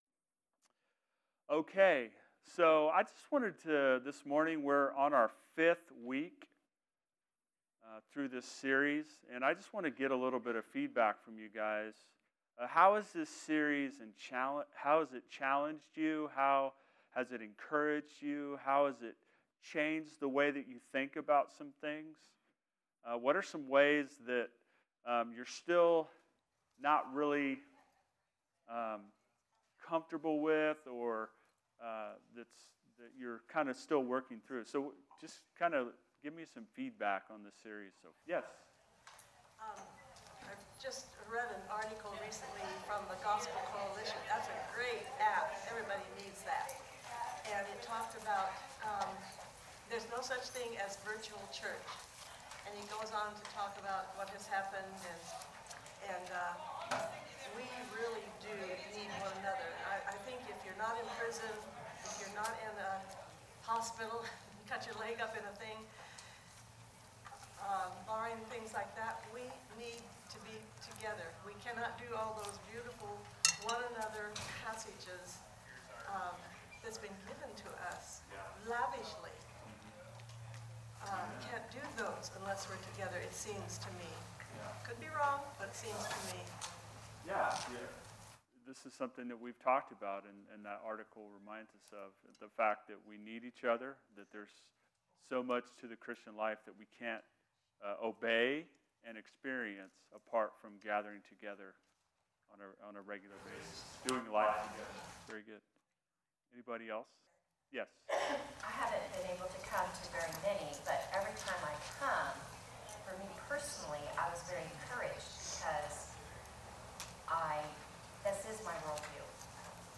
2021 Series: Love Your Church Type: Sunday School